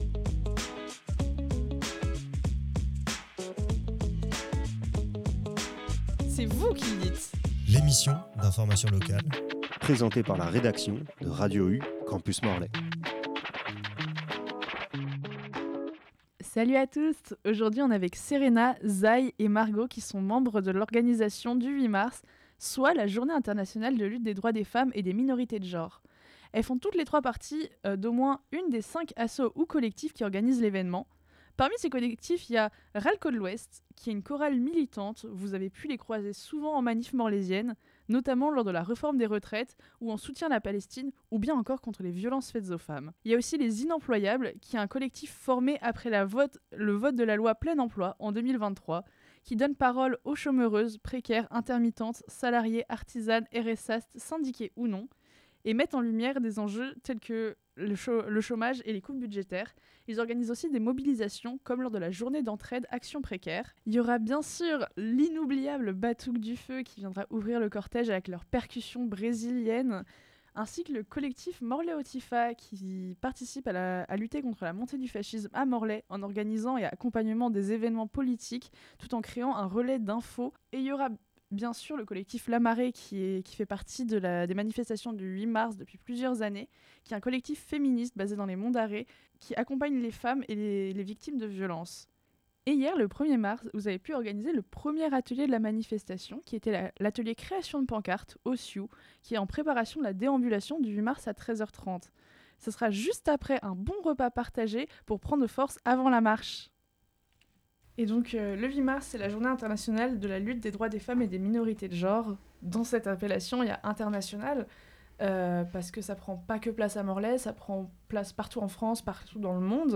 On a pu rencontrer des membres de l’organisation de la manifestation du 8 mars à Morlaix.